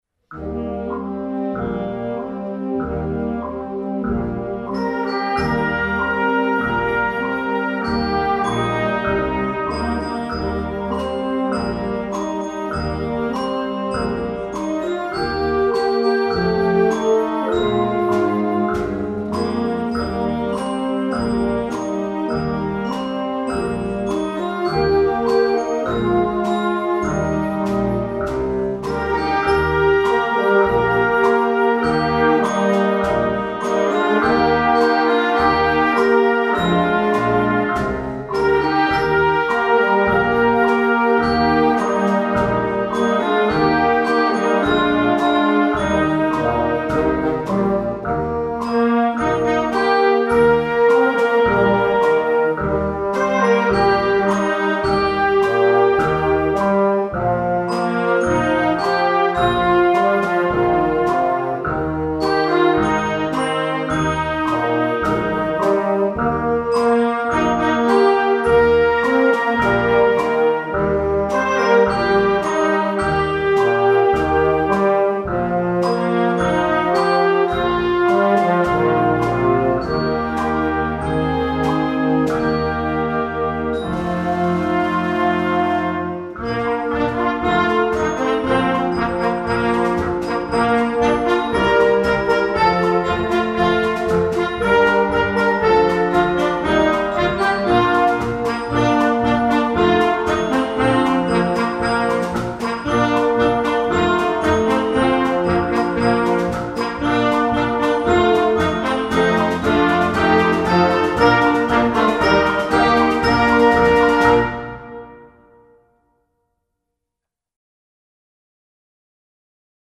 jazz, pop, rock, instructional, children